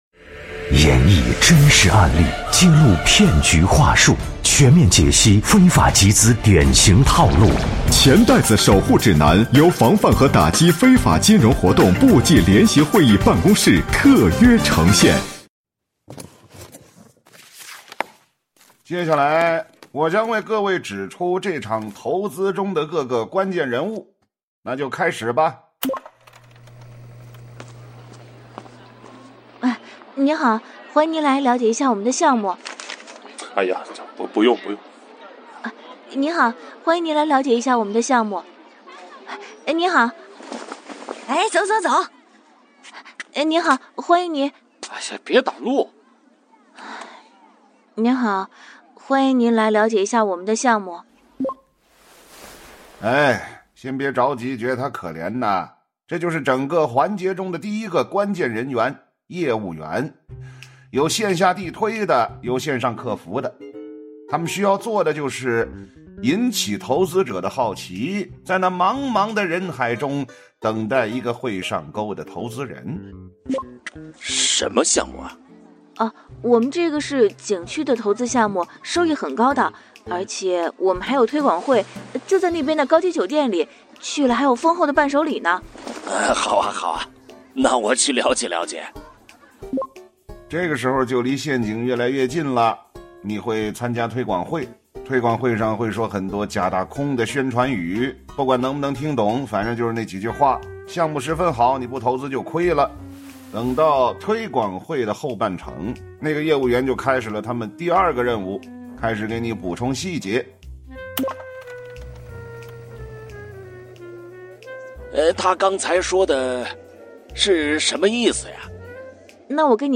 《钱袋子守护指南》是经济之声联合防范和打击非法金融活动部际联席会议办公室特别策划推出的一档防范非法集资科普栏目。选取真实案例，透过典型情节演示非法集资对个人和社会带来的危害，更生动地传递“反非”的理念，增强社会的风险意识和预防能力。